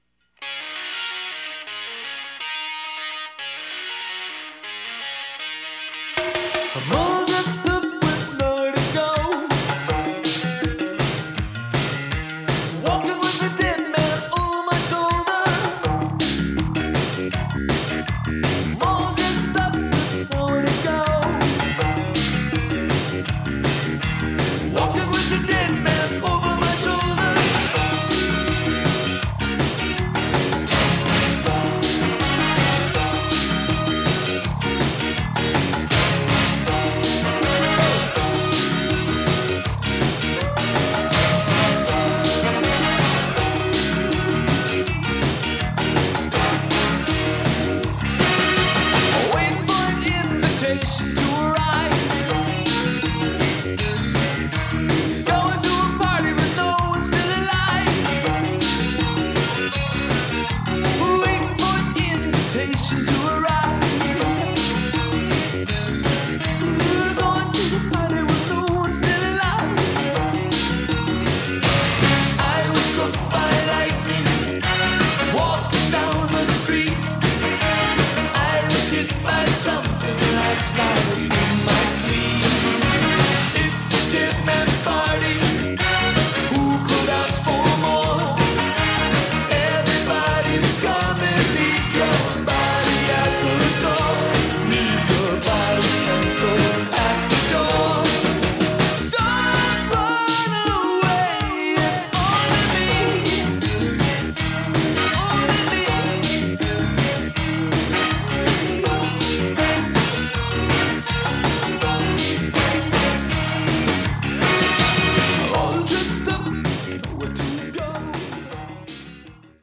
BPM162
MP3 QualityMusic Cut